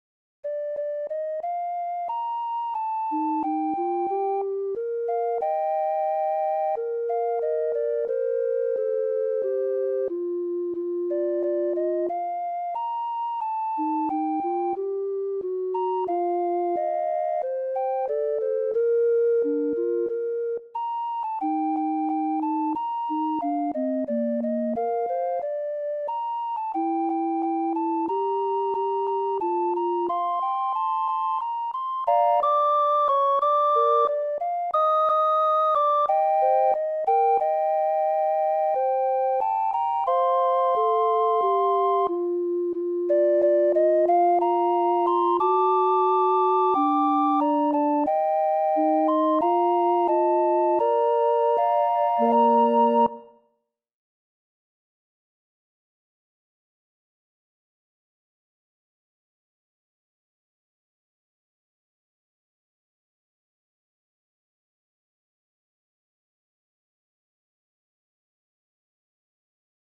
オルゴール夕方バージョン